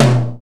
RX GATE TOM.wav